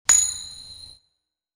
flip sound.
flip.wav